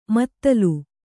♪ mattalu